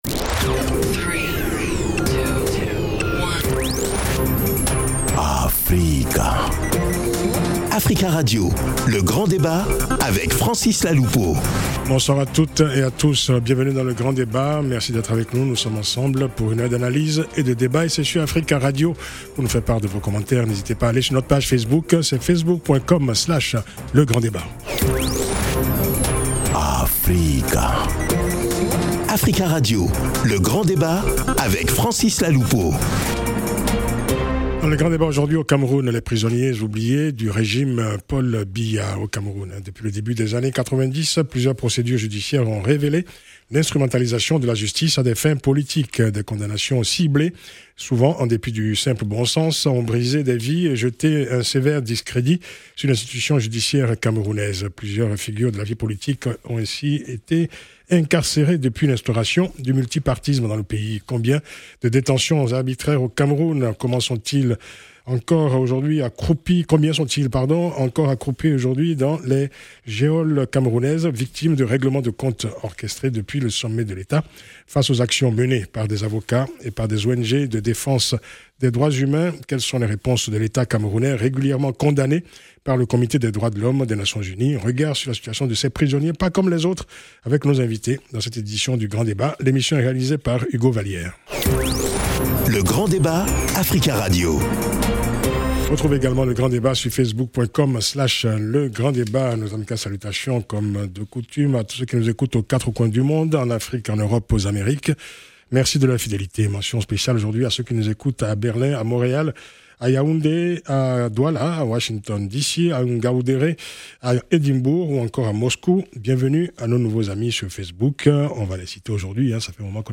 Le Grand Débat – AFRICA RADIO 105.7 FM Paris le 9 Décembre 2019